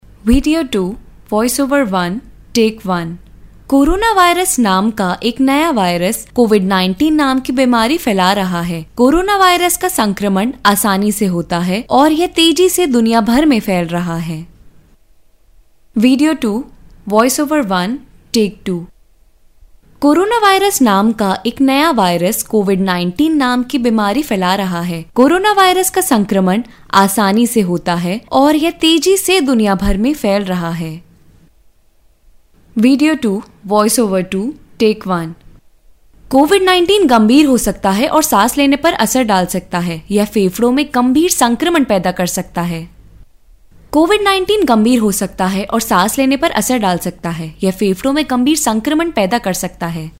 女印02 印度印地语女声 干音 科技感|积极向上|时尚活力|亲切甜美|素人